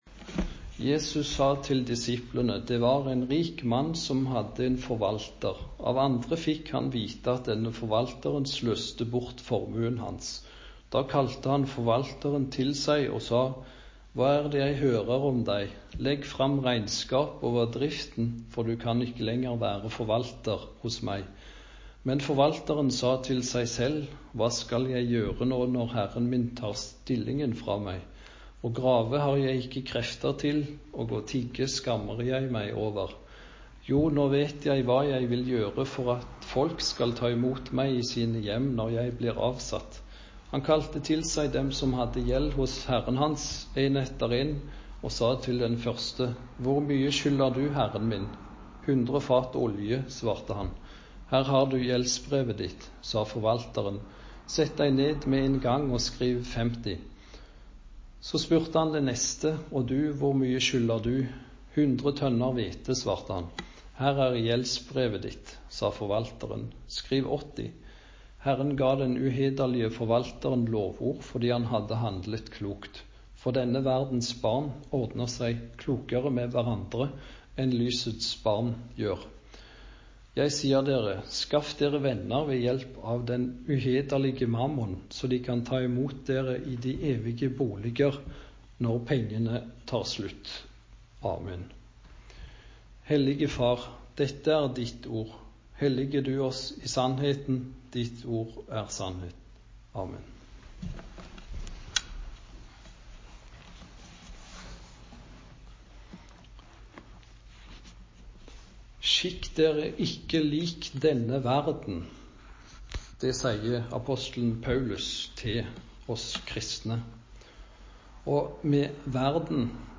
Preken på 9. søndag etter Treenighetsdag